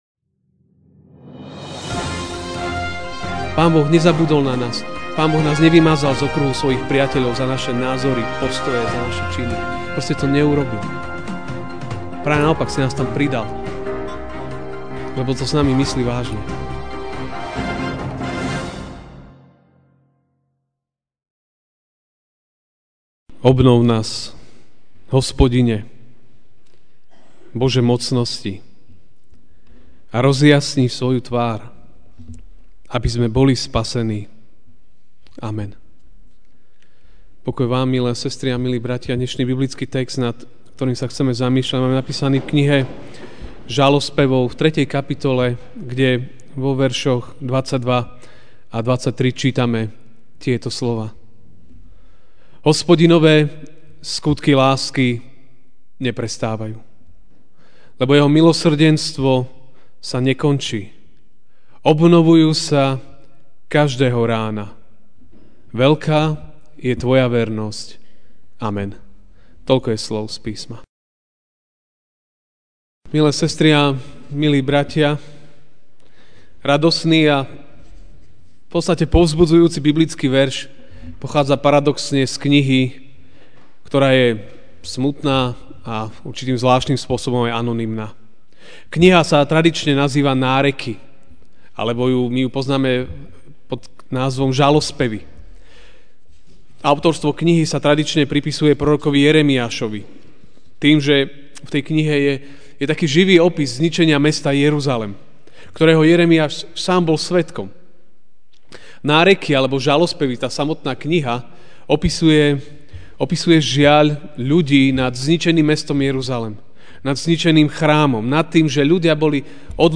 MP3 SUBSCRIBE on iTunes(Podcast) Notes Sermons in this Series Ranná kázeň: Boh, ktorý obnovuje!